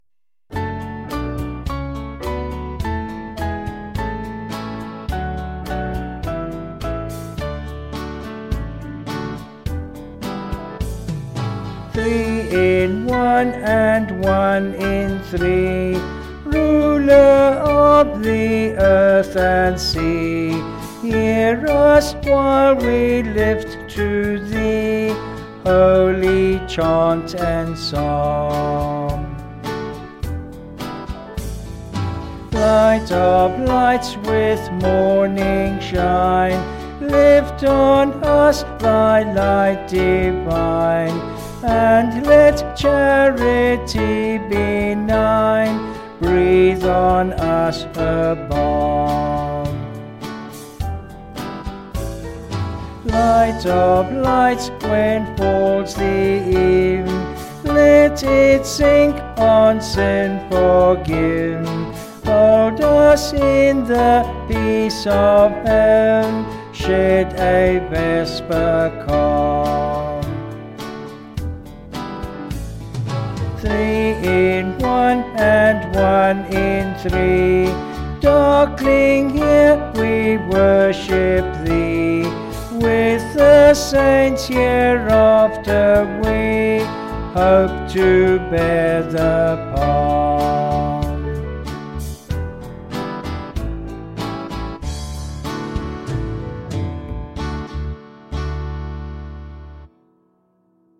Vocals and Band   264.2kb Sung Lyrics